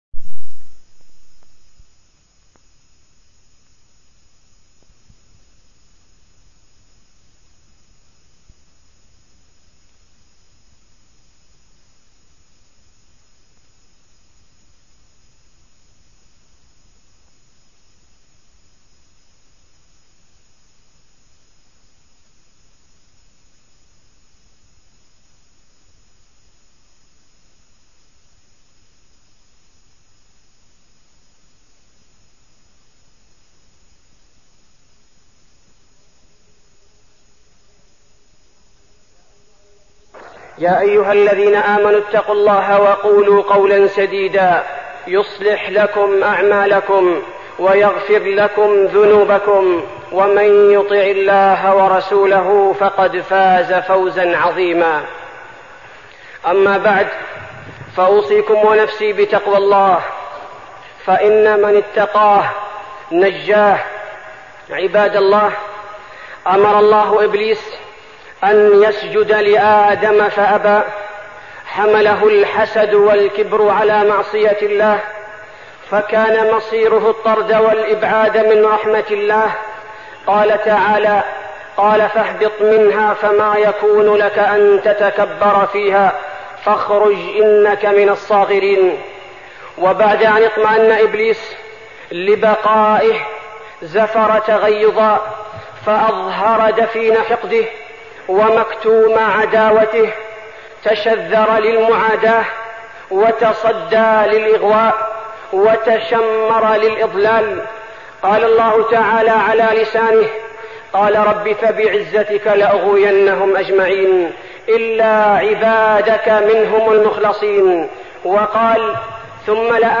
تاريخ النشر ٥ صفر ١٤١٧ هـ المكان: المسجد النبوي الشيخ: فضيلة الشيخ عبدالباري الثبيتي فضيلة الشيخ عبدالباري الثبيتي إبليس عليه لعنة الله